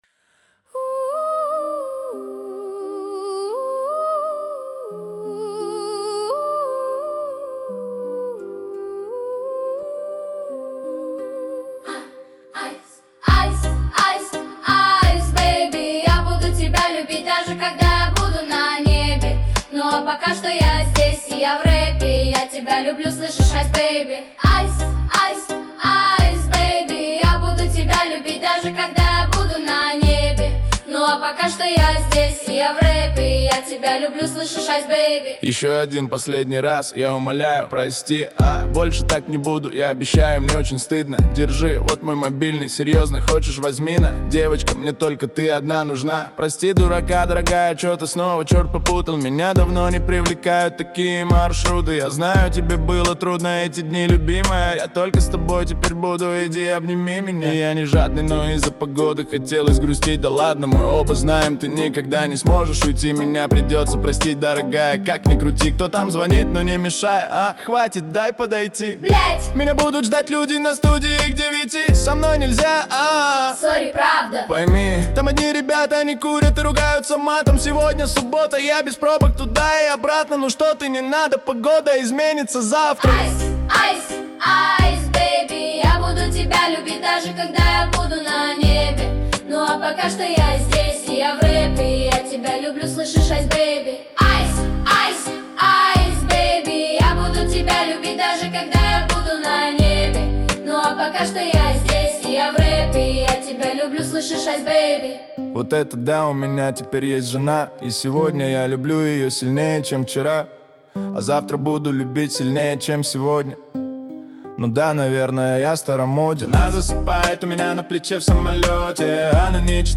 детский хор